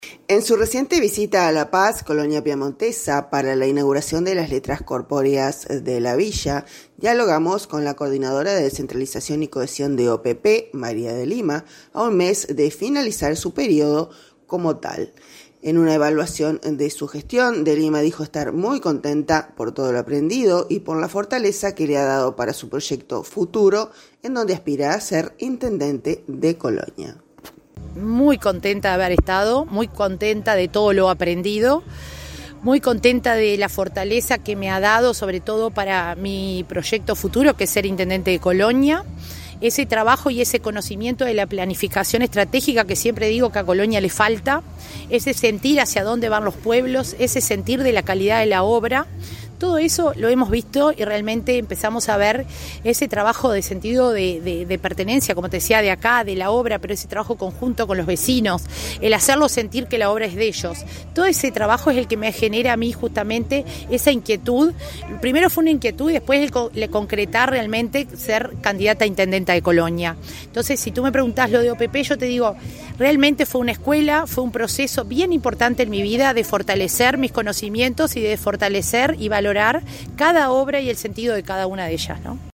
En su reciente visita a La Paz (C.P.) para la inauguración de las letras corpóreas de la Villa, dialogamos con la Coordinadora de Descentralización y Cohesión de OPP, María de Lima, a un mes de finalizar su período como tal.